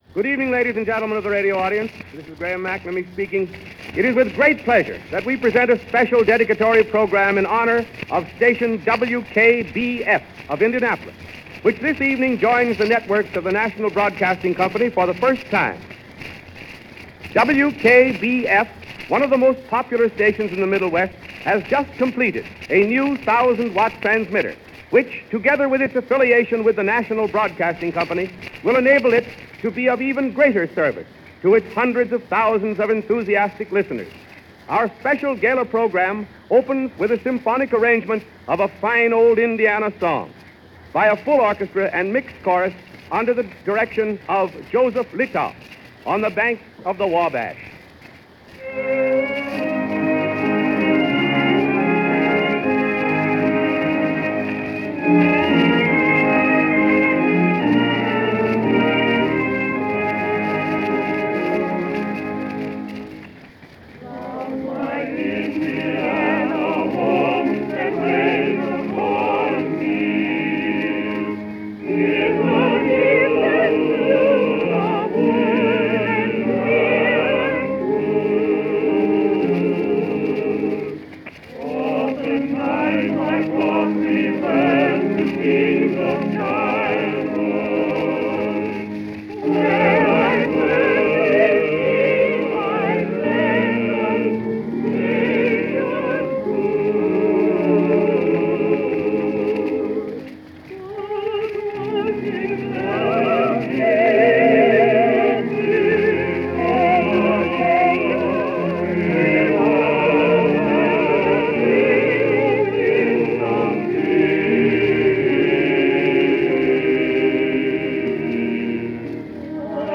August 31, 1933 - Celebrating in Depression Era America - the dedication of a Radio station joining the NBC Radio Network.
On its surface, this broadcast of a radio station dedication ceremony is quaint and strange and most likely hard to listen to for many people – not so much that the quality runs the risk of being crude, but the sentiment is something almost totally foreign to us these days. People don’t talk like that anymore – people don’t say those things anymore – the music is strange and foreign; the whole thing smacks of something requiring an infinitely longer attention span than most of us would be willing to give in 2022.